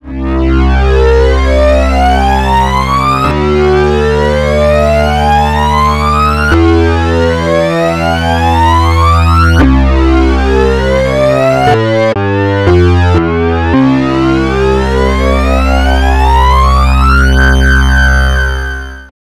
15.01.2023Prophet 5 VST "Prophanity" Sound Demo to show that a 32 Bit vsti can sound great.
Sound Demo:
Prophet5VST_Prophanity_Sound_Demo.wav